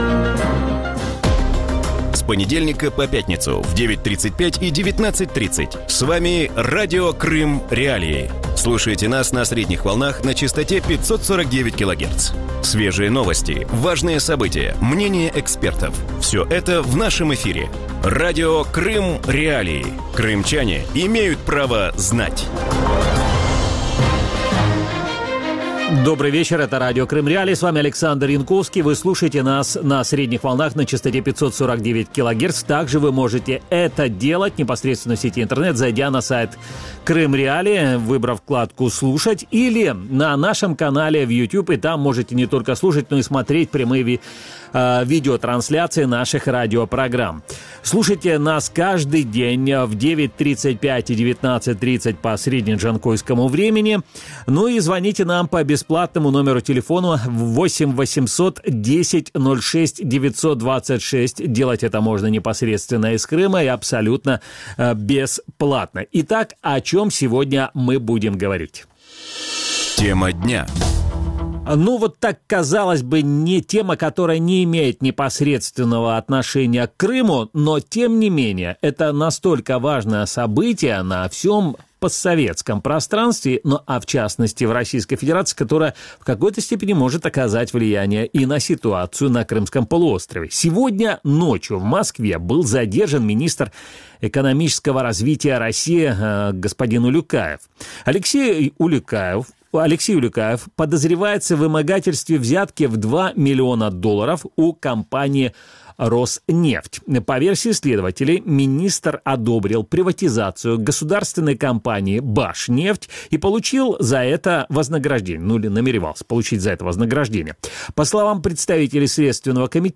В вечернем эфире Радио Крым.Реалии говорят о задержании министра экономического развития России Алексея Улюкаева.
поговорит с российскими политологами